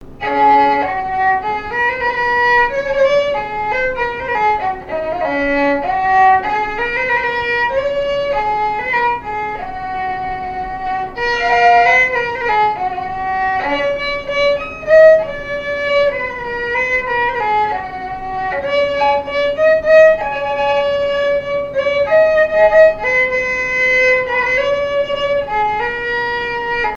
Répertoire de marches de noce et de danse
Pièce musicale inédite